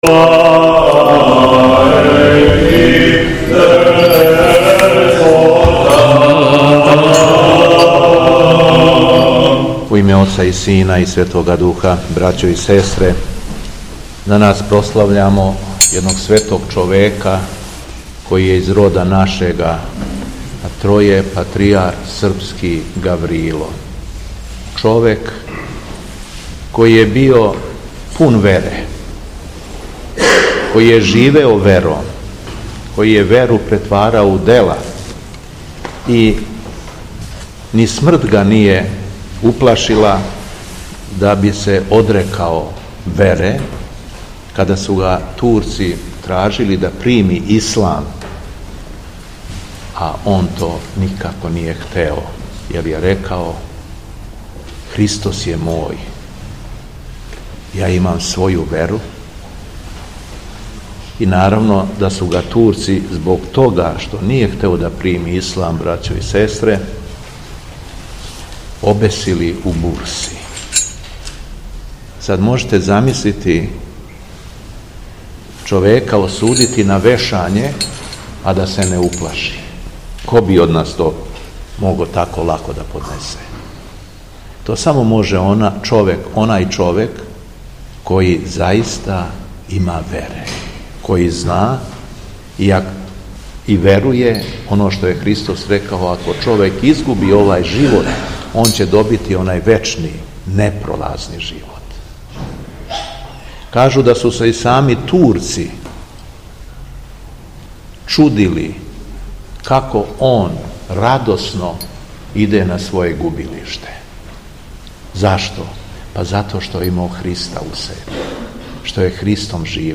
У четвртак 26. децембра 2024. године, Његово Високопресвештенство Митрополит шумадијски Г. Јован служио је Свету Литургију у Старој Цркви у Крагујевцу...
Беседа Његовог Високопреосвештенства Митрополита шумадијског г. Јована